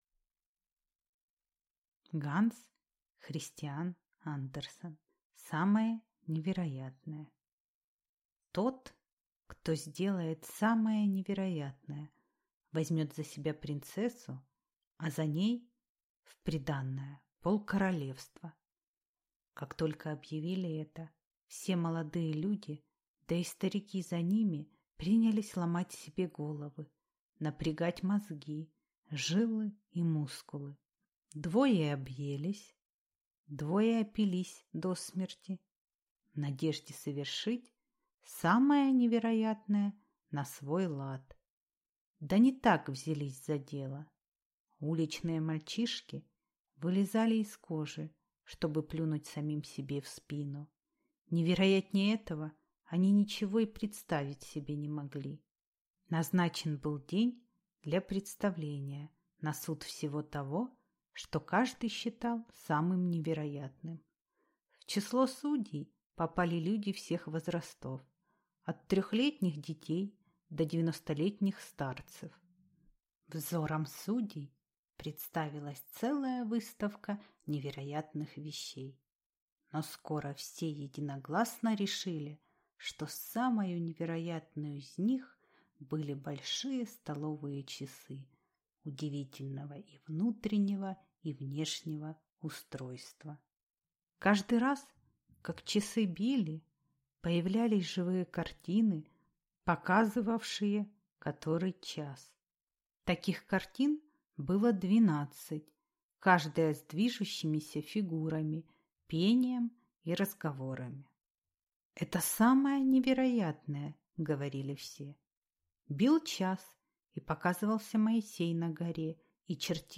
Аудиокнига Самое невероятное | Библиотека аудиокниг